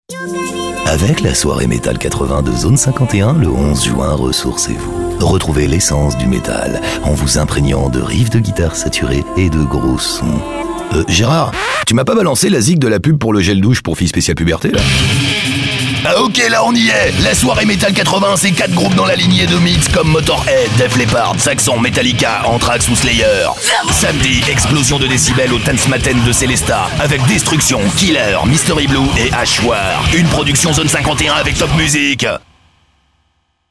mp3 PUB Radio SELESTAT (33 secondes / 793 Ko)